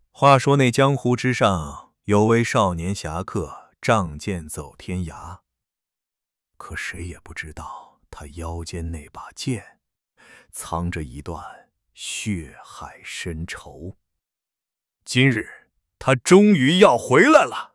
智东西上传了一段茶馆说书人的音频标签文本，提示词为
整体来看，音频中的三句话都符合前面的音频标签特征，但每一句之间的衔接仍有优化的空间，会出现声音突然从高变低，又突然拔高的情况。